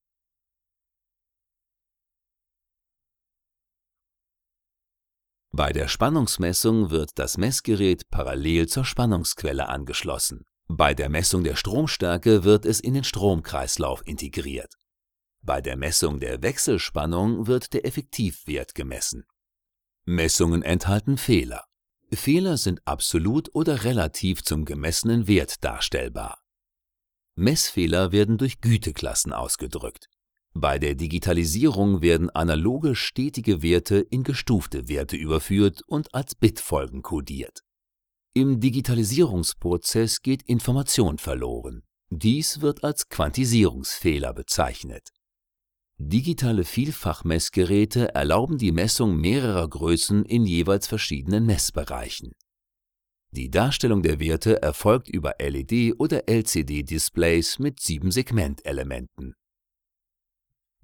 Sprechprobe: eLearning (Muttersprache):
E-Learning_Stromstärkemessung.mp3